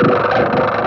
7 Boiling In Dust Techno Noisy Guitar.wav